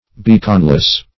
Beaconless \Bea"con*less\